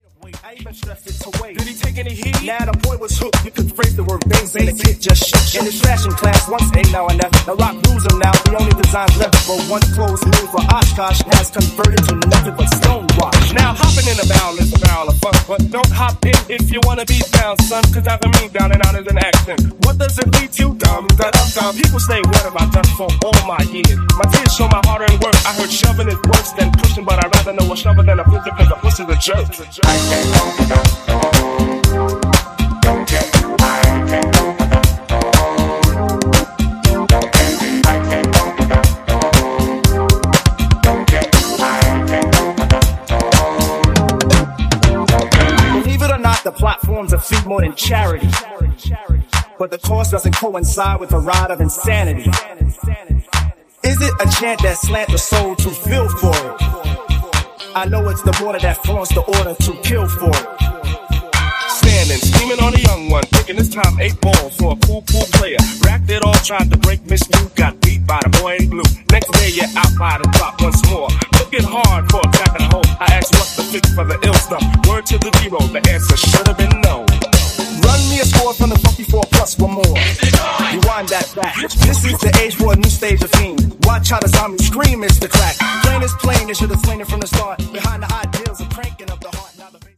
a housed up groovy vocoder laden version